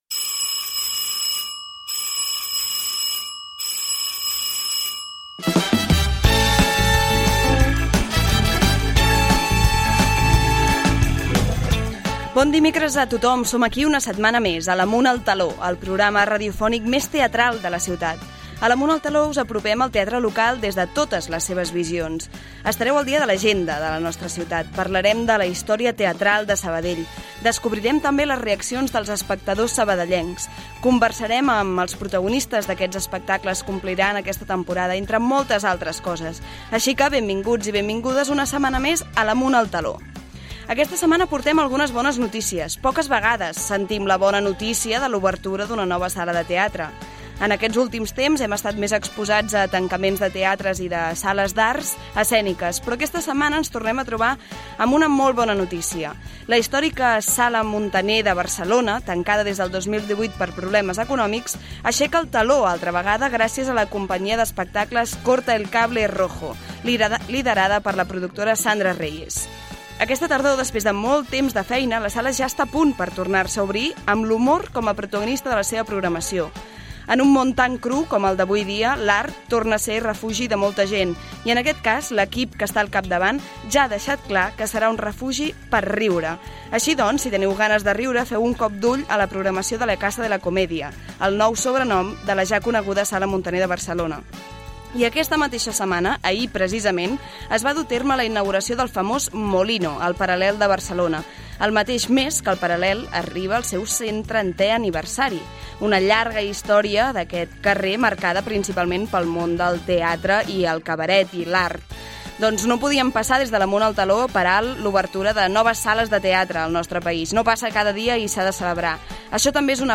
Amunt El Teló 30/10/24 - Entrevista sobre el musical Lizzie, una producció sabadellenca al Teatre Gaudí. Repassem la història del Teatre Sant Vicenç. I parlem de la cartellera teatral barcelonina.